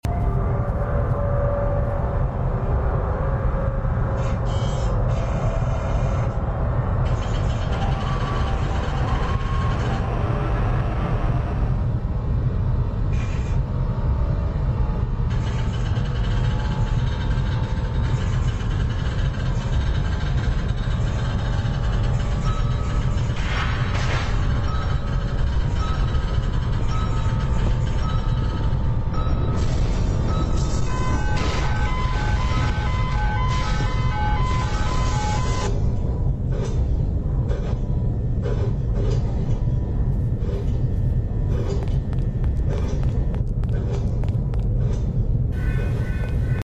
backrooms threshold door power is sound effects free download